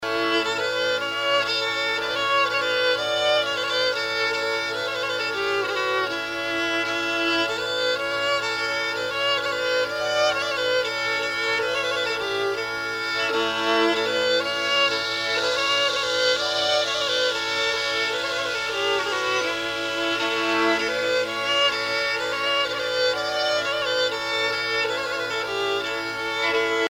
Suite de marche de noces
circonstance : fiançaille, noce
Pièce musicale éditée